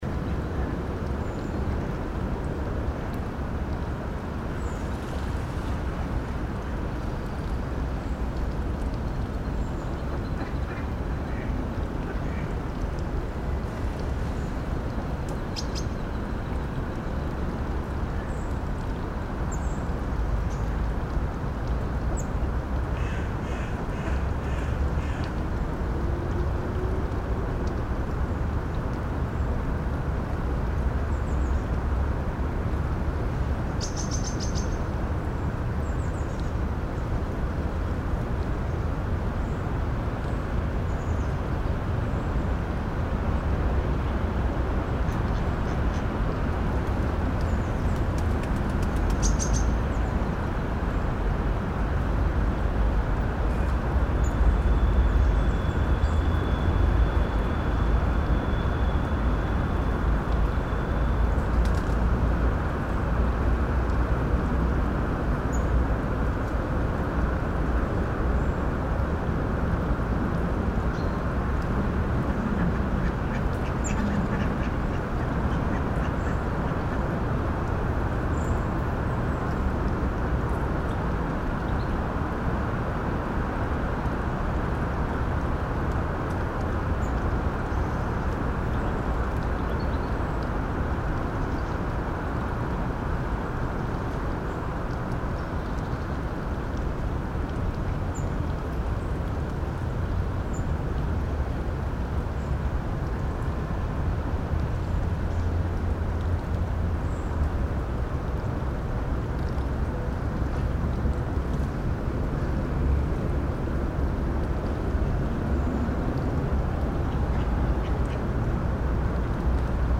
the eight recordings that constitute "soundmap vienna" originate partly from locations inside the city that bear an everyday relationship with me and partly have been "discovered" during a few occasional strolls. what they have in common is that they don’t bear an apparent reference to vienna, neither acoustically nor visually – no fiakers and no vienna boys choir, sorry for that – and that most of them were taken at unusual times during the day. these recordings were left unprocessed and subsequently arranged into a 19-minute long collage. the second, more experimental collage was composed out of midi-data and soundfiles that have been extracted via specific software from the photographs taken at the recording sites.